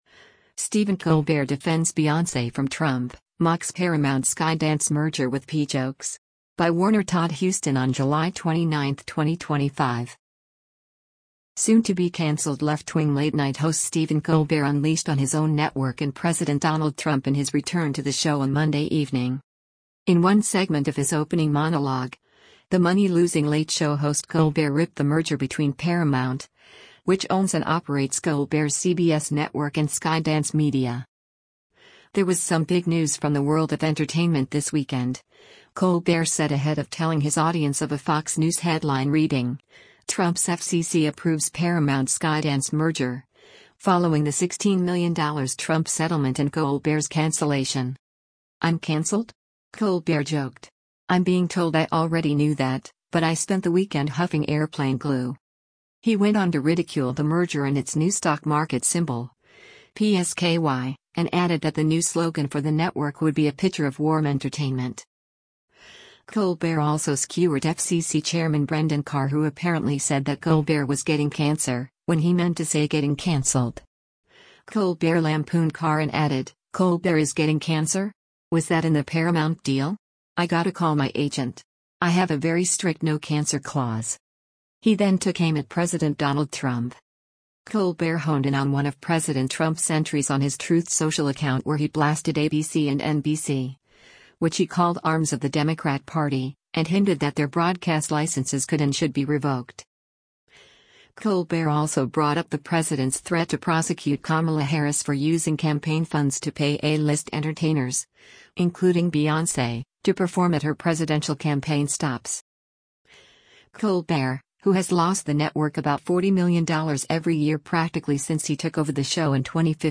Soon-to-be canceled left-wing late-night host Stephen Colbert unleashed on his own network and President Donald Trump in his return to the show on Monday evening.
In one segment of his opening monologue, the money-losing Late Show host Colbert ripped the merger between Paramount, which owns and operates Colbert’s CBS network and Skydance Media.
In his faux musical response, he pushed out a “song” linking Donald Trump to convicted sex predator Jeffrey Epstein.